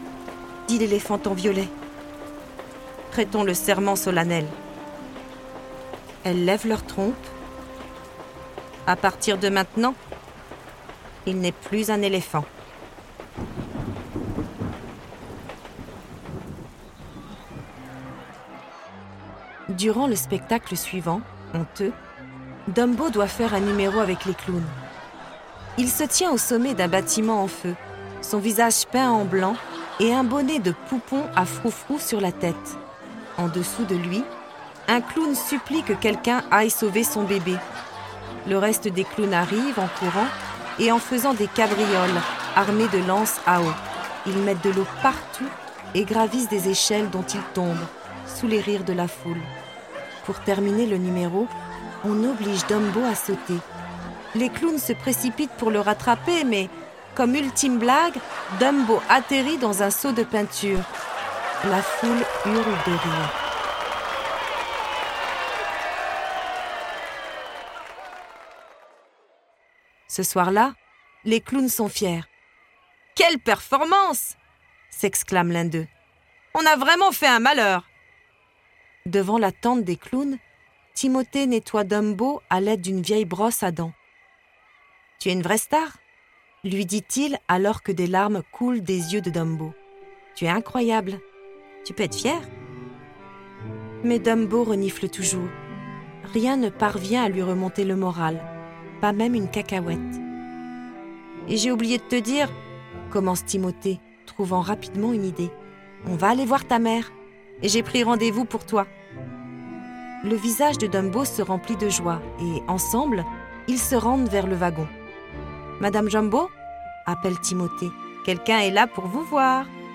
09 - Chapitre 09_ Dumbo - L'histoire à écouter_ Dumbo.flac